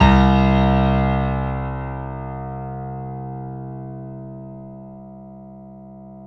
SG1 PNO  C 1.wav